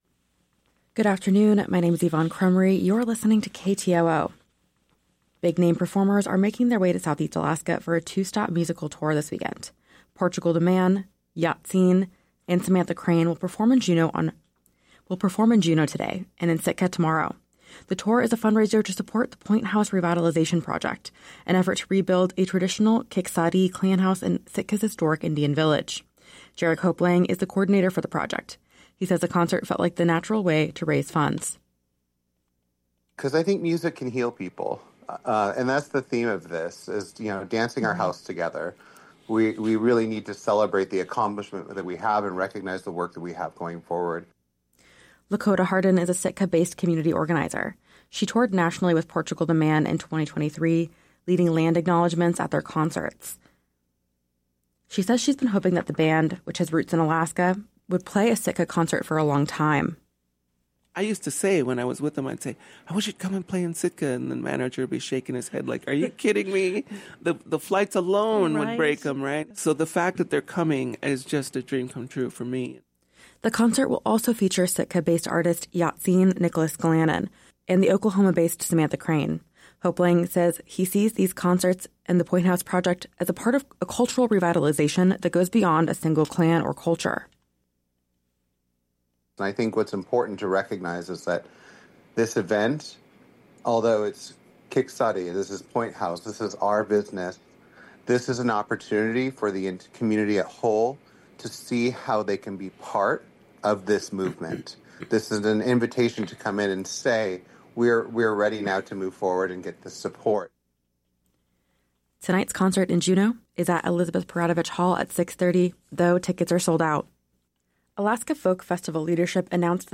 Newscast – Friday, Jan. 31, 2025 - Areyoupop